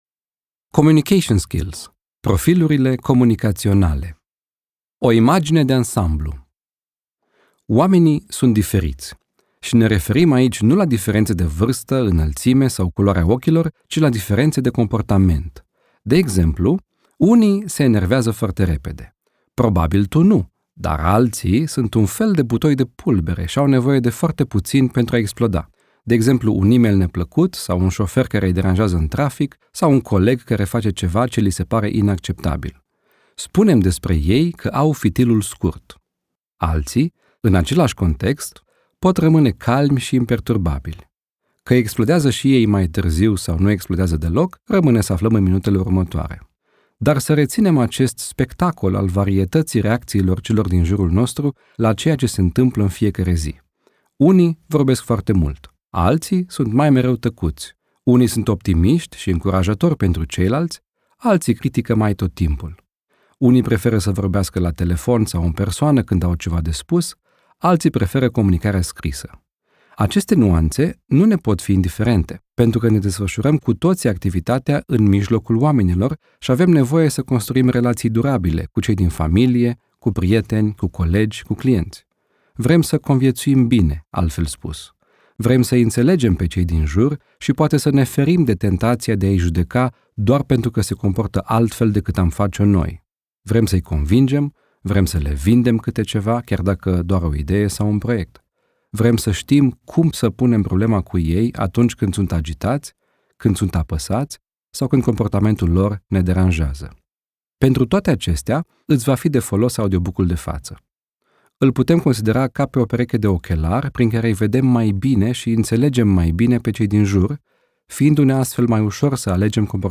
Ascultă aici, dacă vrei, primul capitol al celui mai recent audiobook, Communication Skills – Profilurile Comunicaționale. Capitolul include și un link la care să-ți faci on-line un assessment al profilului tău comunicațional.